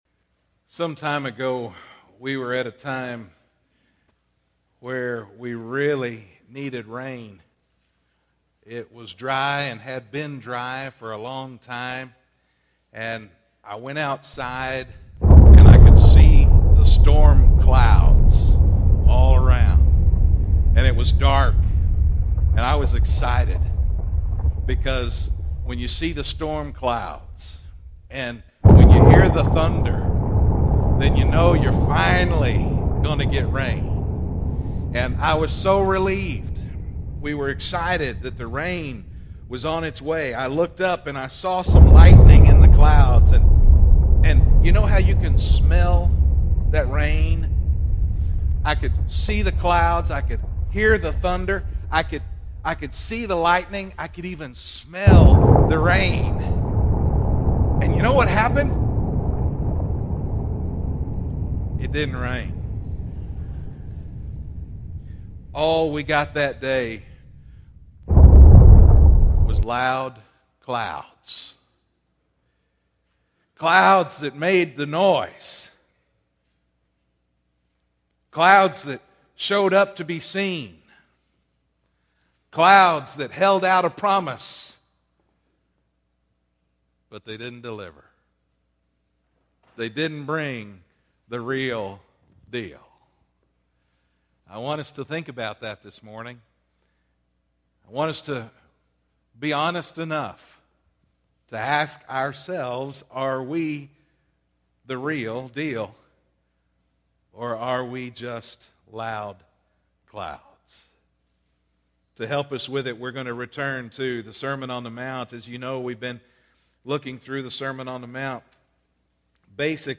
Spoiler alert: you might want to turn the volume down a little at the beginning of this one.